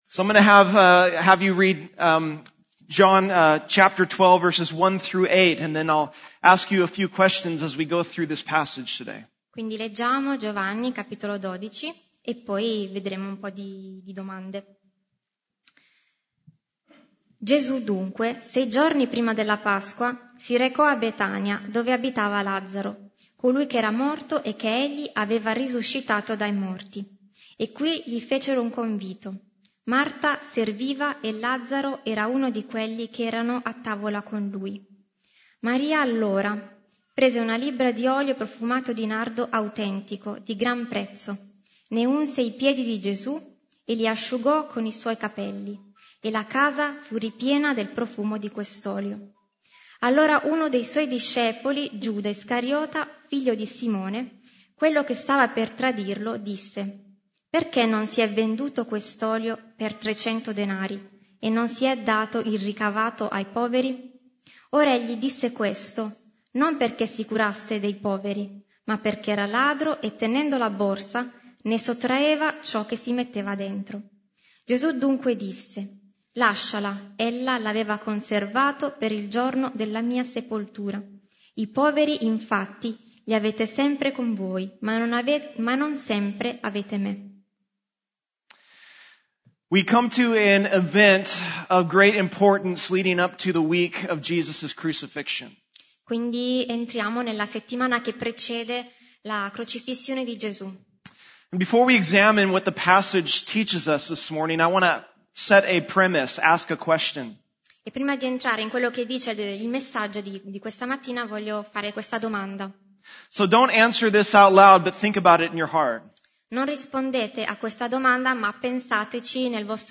Messaggio di Domenica 20 Ottobre